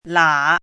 “拉”读音
lǎ.mp3